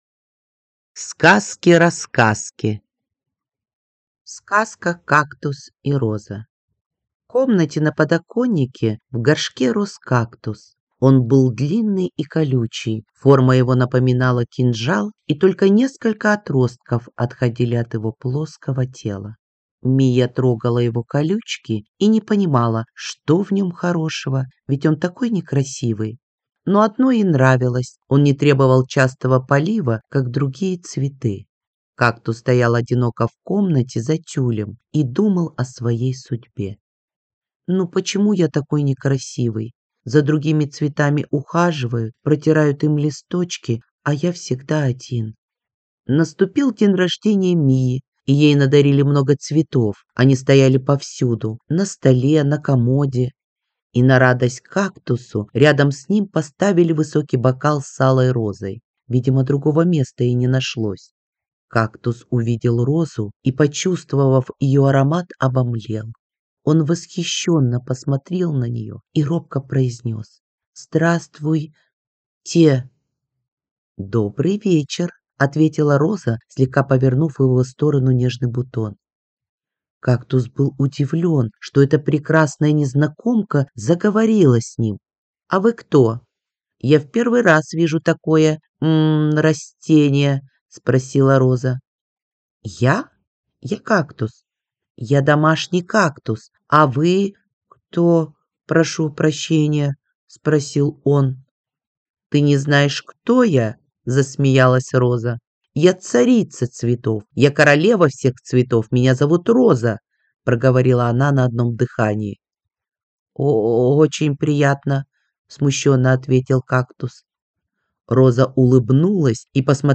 Аудиокнига Сказки – Рассказки | Библиотека аудиокниг